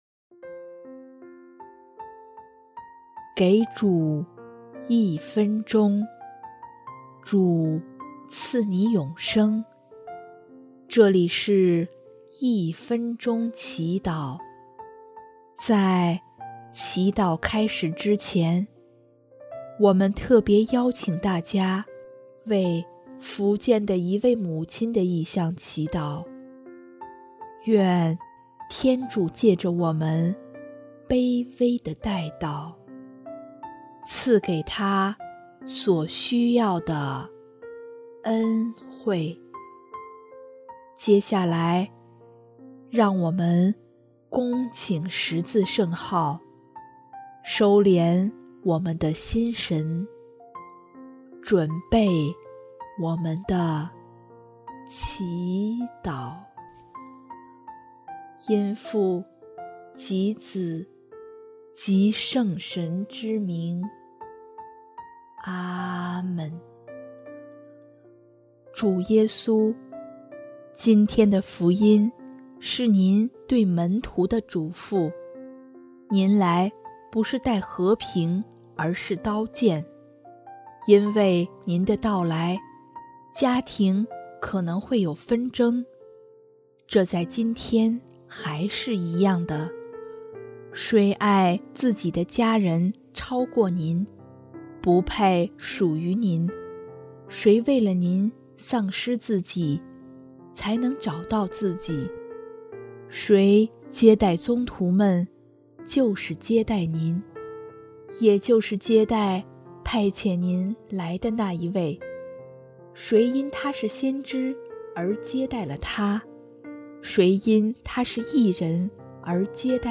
音乐： 主日赞歌《活水》（福建的一位母亲：为孩子祈求手术平安顺利，祈求治愈）